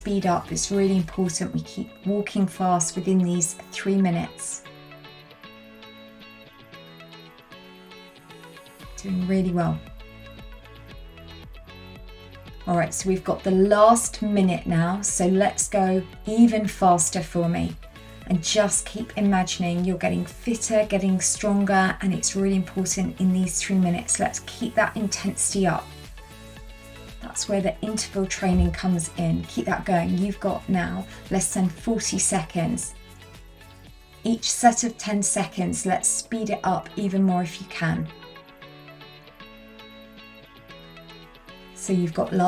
A 30-Minute Audio Download for Weight Loss and Better Health
This is a full 30-minute audio file with coaching & background music.
Example-of-Japanese-Walking-Workout-Audio.mp3